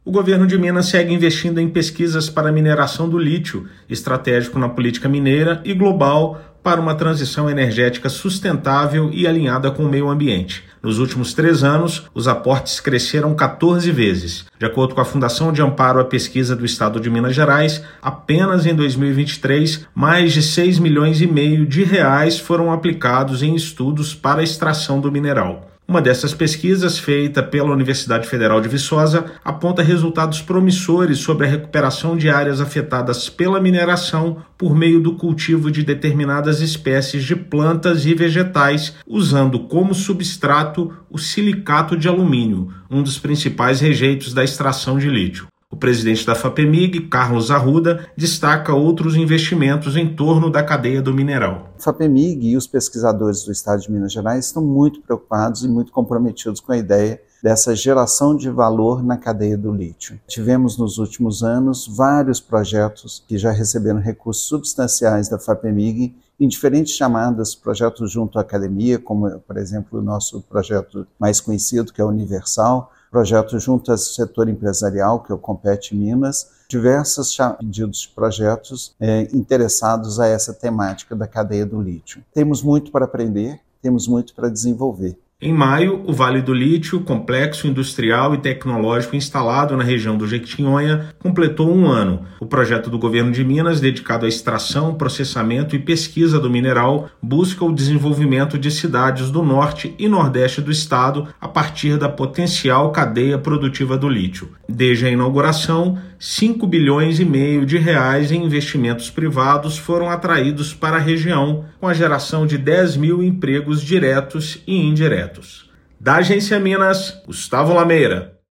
[RÁDIO] Investimentos do Governo de Minas em pesquisas para mineração responsável do lítio crescem 14 vezes em três anos
Só em 2023, Estado destinou, via Fapemig, mais de R$ 6,6 milhões para estudos relacionados à extração do mineral. Ouça a matéria de rádio: